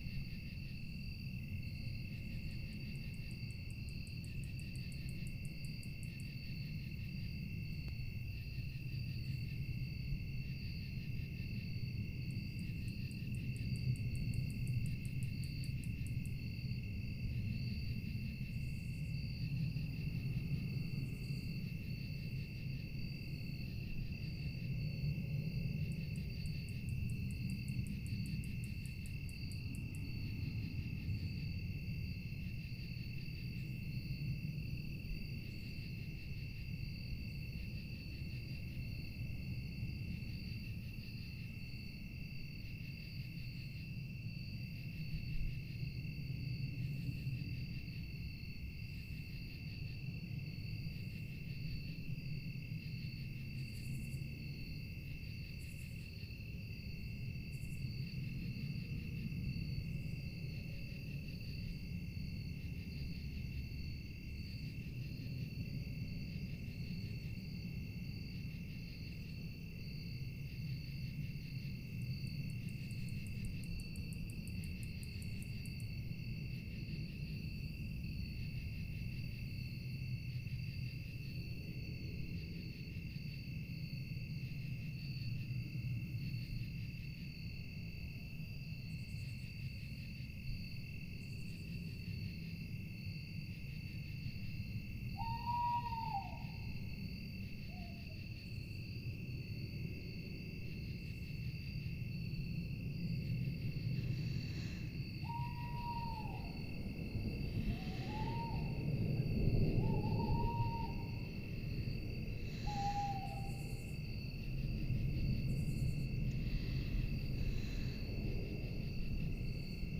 forest-night.wav